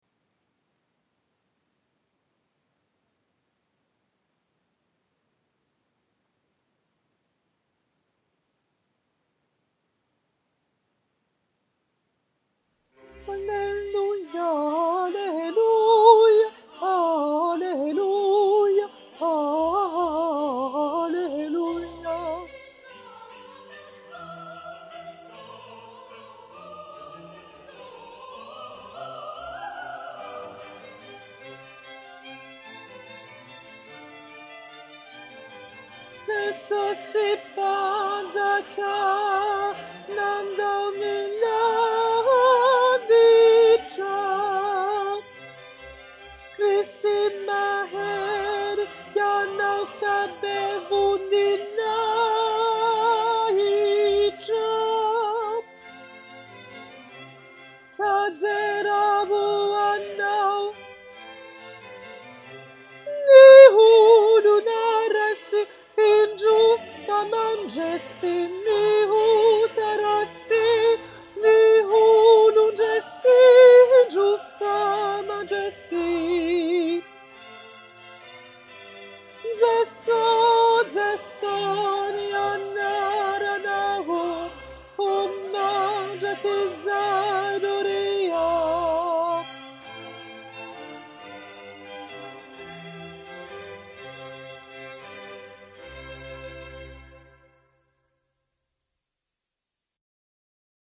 cantate_142_alto-23_04_2014-21-36.mp3